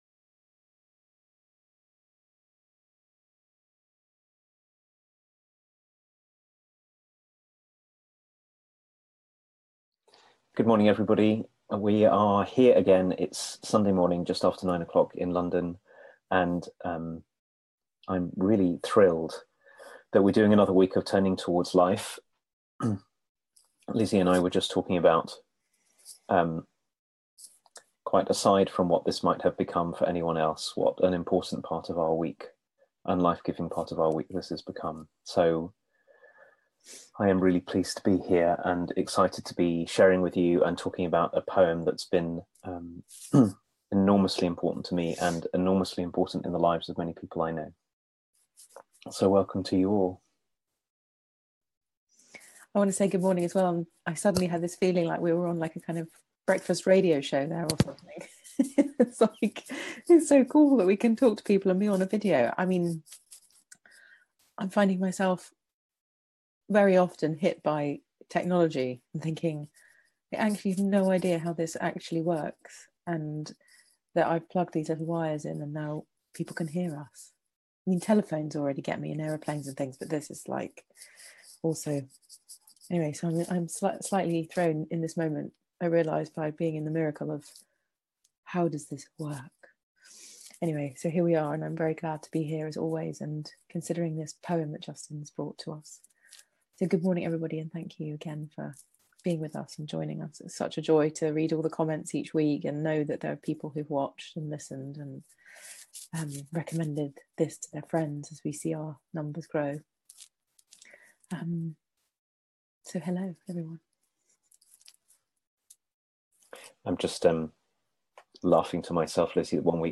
a weekly live 30 minute conversation